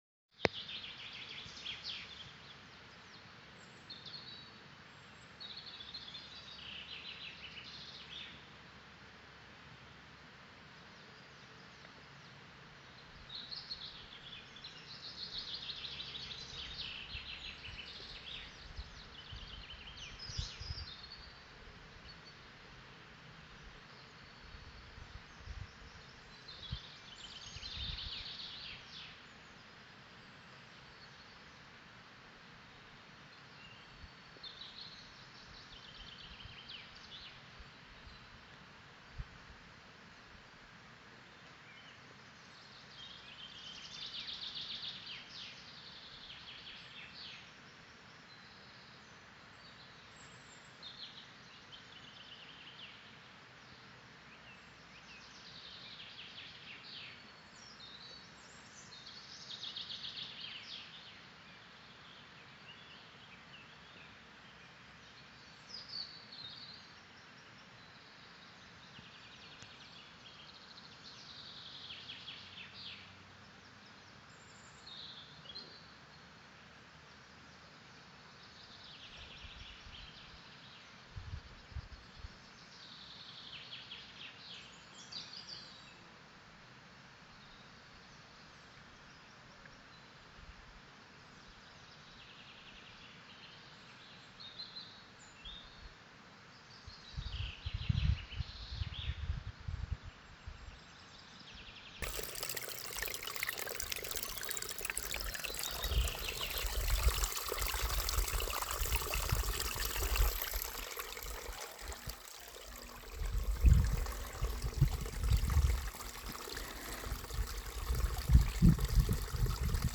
Soutěžní výzva Krajinou inspirace Zaposlouchejte se do zvukových záznamů krajiny, které natočili návštěvníci před vámi. Zkuste se na ně naladit a přeneste se do sdílené přírody.
Vánek, křupání sněhu, zurčení vody…